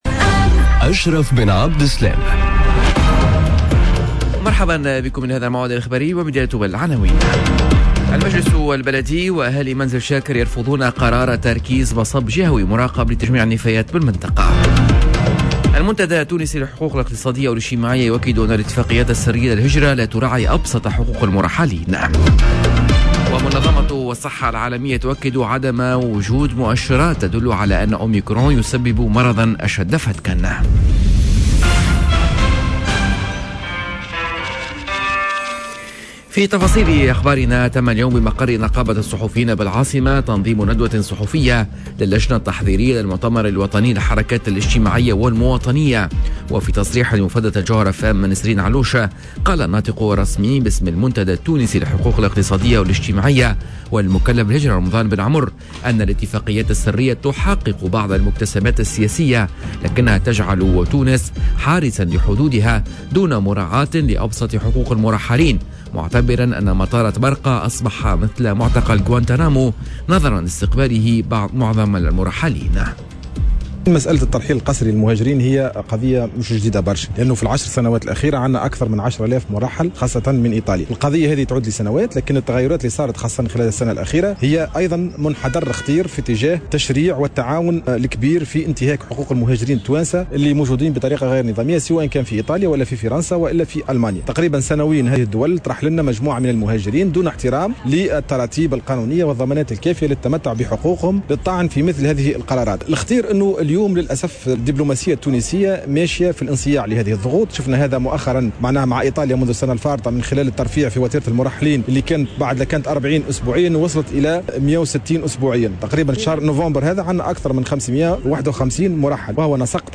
نشرة أخبار السابعة صباحا ليوم الإربعاء 08 ديسمبر 2021